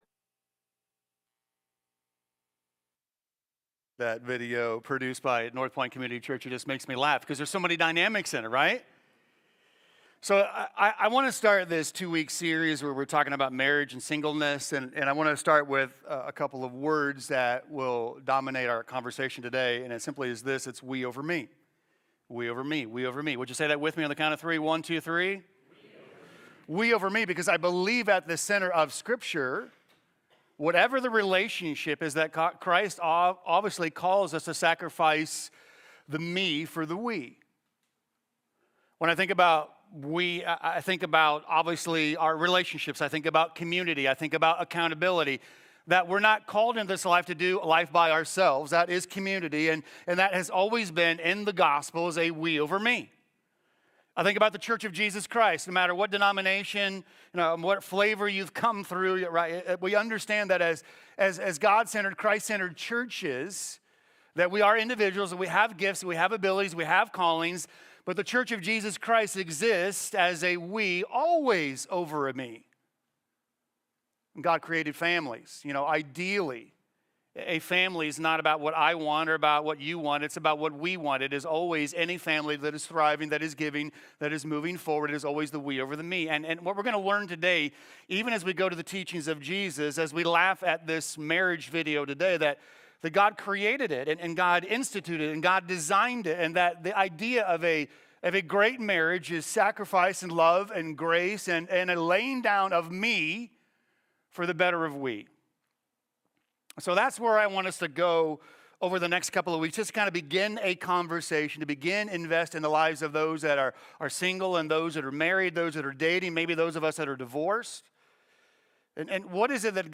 Sermons – Commonway Church